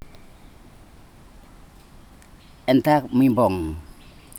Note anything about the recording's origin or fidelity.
digital wav file recorded at 44.1 kHz/16 bit on Marantz PMD 620 recorder; ELAN eaf file Sesivi, Ambrym, Vanuatu